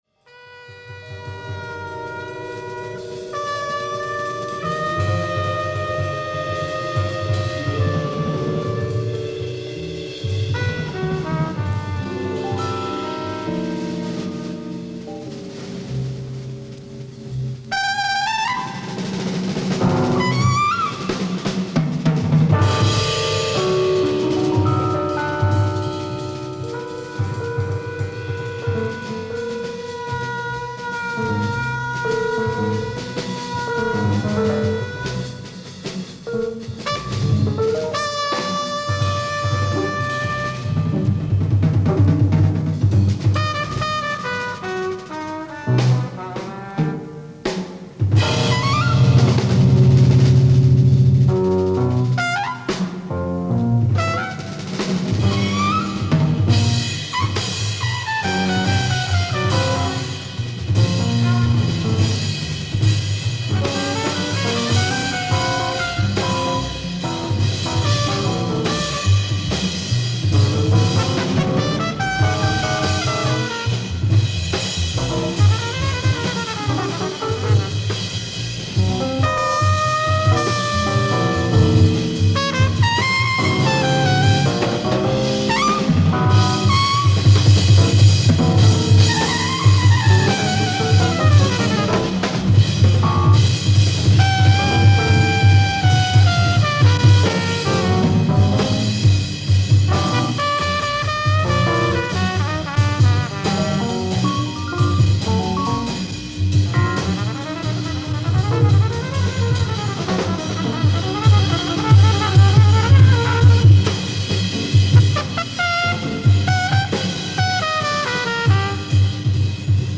ライブ・アット・ウィーン、オーストリア 10/31/1969
※試聴用に実際より音質を落としています。